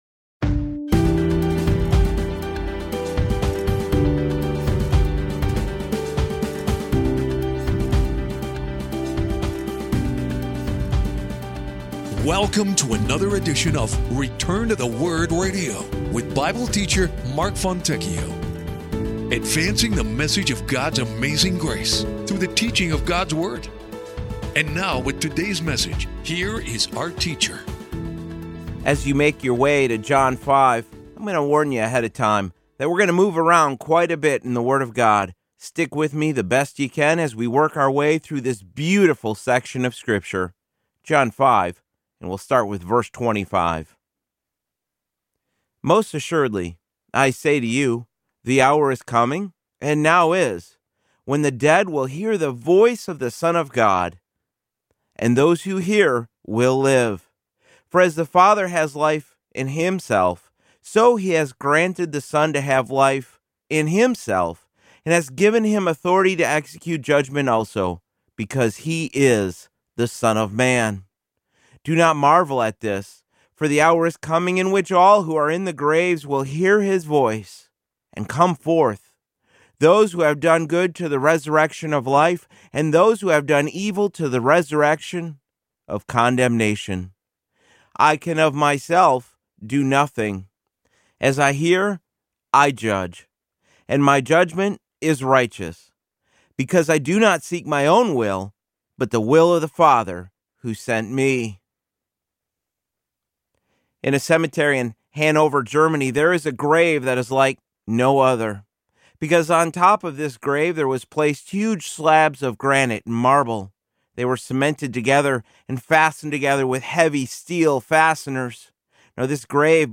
Teaching God's Word and advancing the message of His amazing grace one verse at a time.